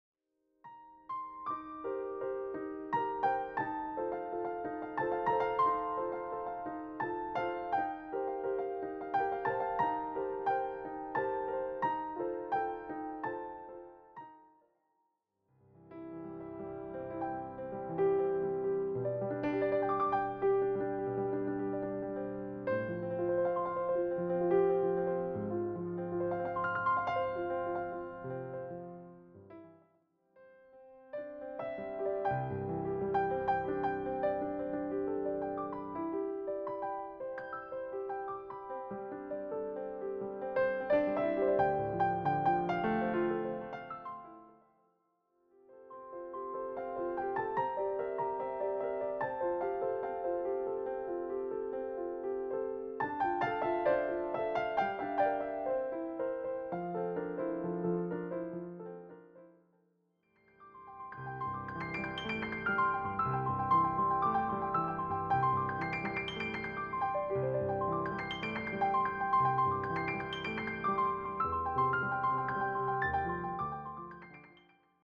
all arranged and performed as solo piano pieces.
tempos flex naturally, melodies are given room to linger